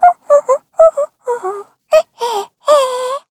Taily-Vox_Hum_kr.wav